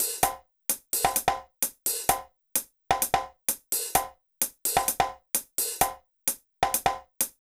BAL Beat - Mix 9.wav